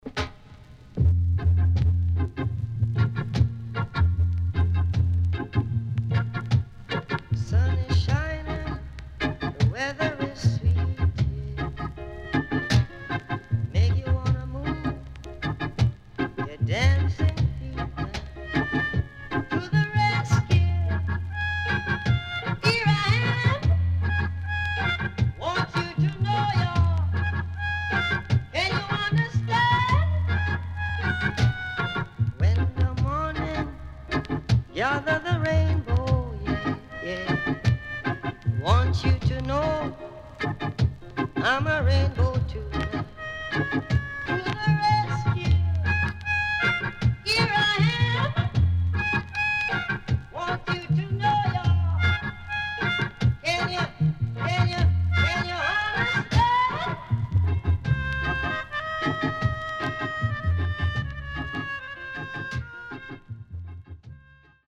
SIDE B:全体的にプレス起因のチリノイズ入ります。